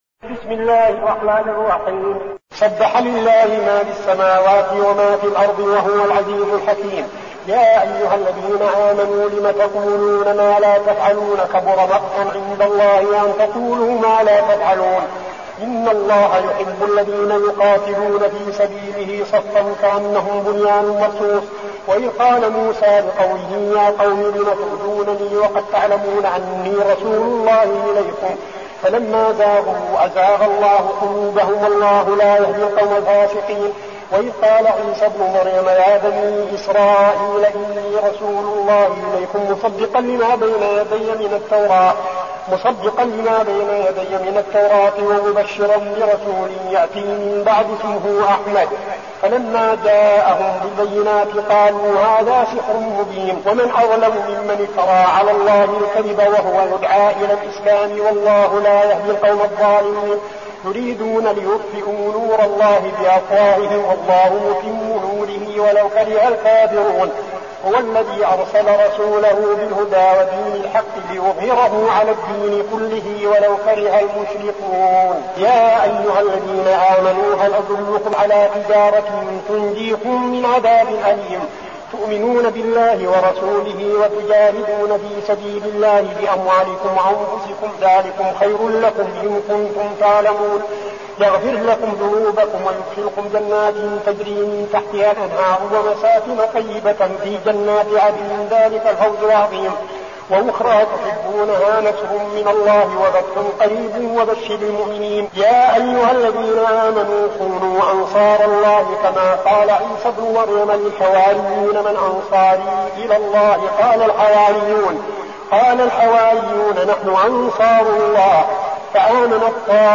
المكان: المسجد النبوي الشيخ: فضيلة الشيخ عبدالعزيز بن صالح فضيلة الشيخ عبدالعزيز بن صالح الصف